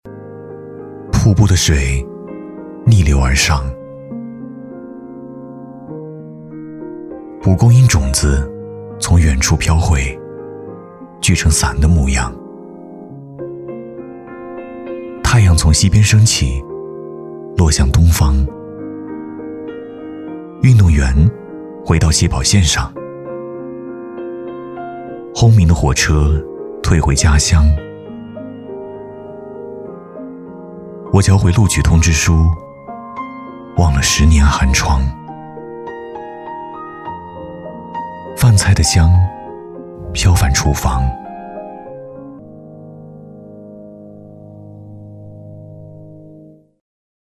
男国195-公益广告 磁性温暖
男195-大气主流 质感磁性
男国195-公益广告 磁性温暖.mp3